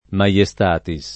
maiestatico [ ma L e S t # tiko ]